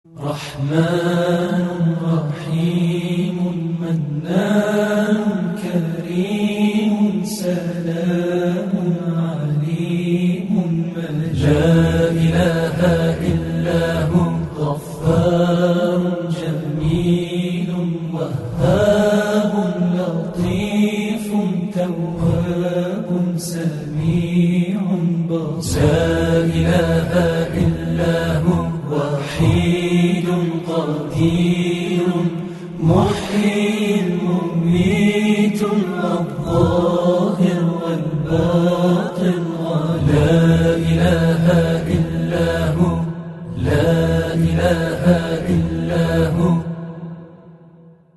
غم شیرین و قشنگی که داره رو دوست دارم؛